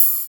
50 OP HAT 2.wav